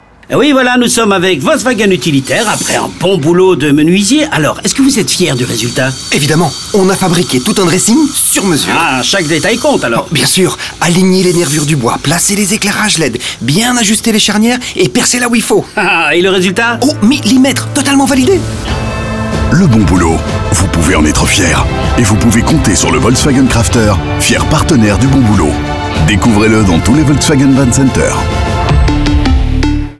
Le volet radio se focalise aussi sur les professionnels, dans des séries de 3 spots radio où ils parlent fièrement de leur boulot comme des sportifs parlent fièrement de leurs prestations.
Menuisier_FR.mp3